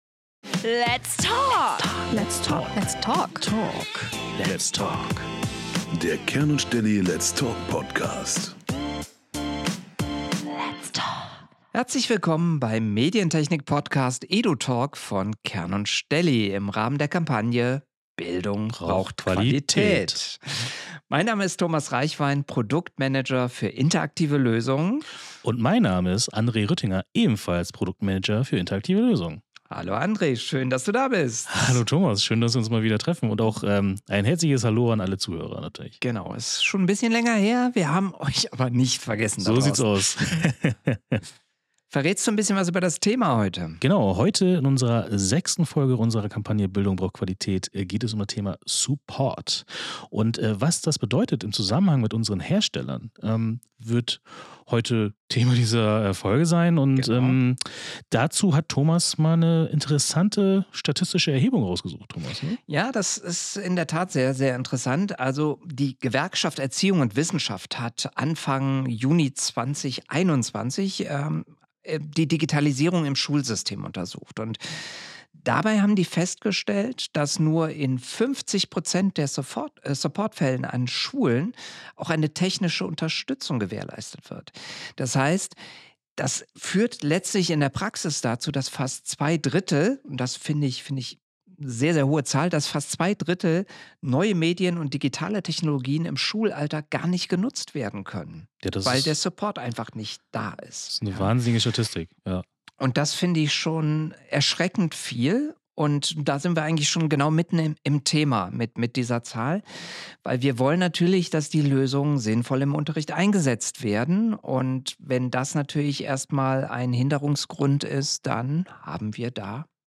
Was tun, wenn die Technik einmal streikt? Wie wichtig guter Support ist und welche Gefahren existieren, wenn man schlechten oder gar keinen Support erhält, diskutieren unsere beiden Kern & Stelly Experten für interaktive Lösungen.